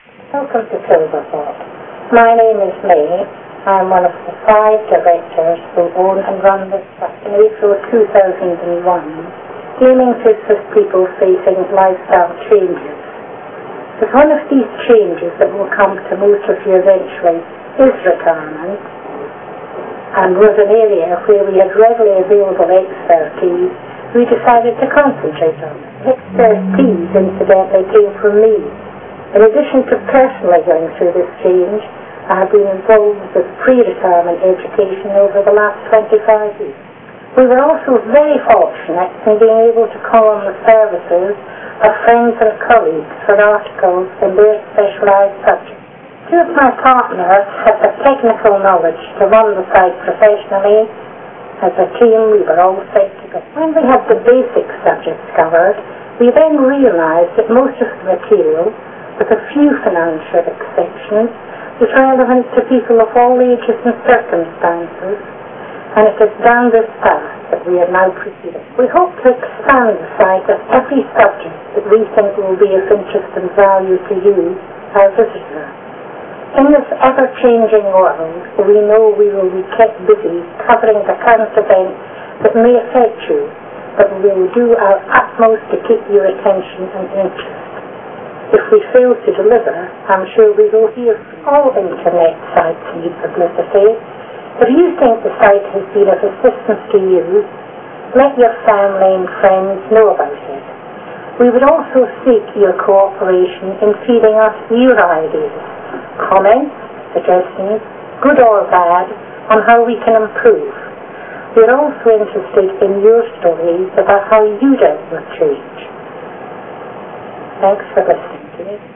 Speaker Commentary Only